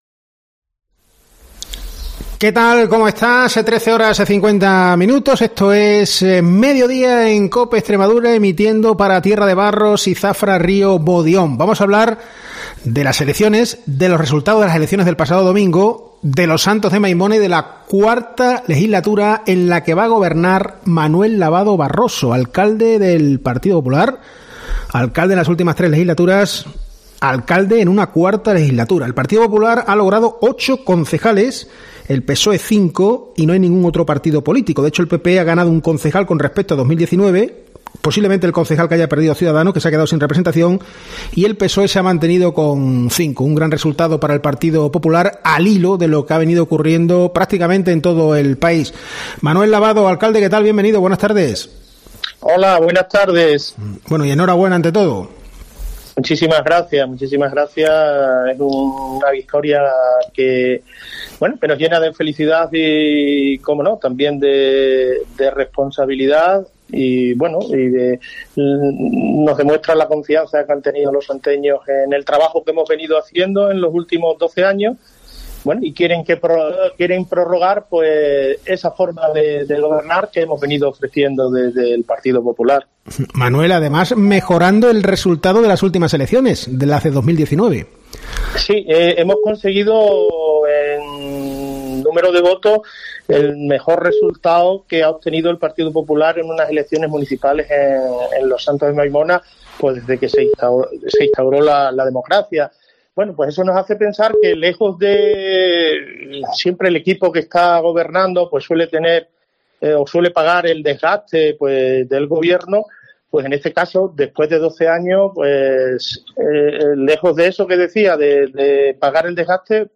En COPE hemos hablado con el alcalde en funciones, alcalde electo, Manuel Lavado.